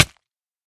hit3.mp3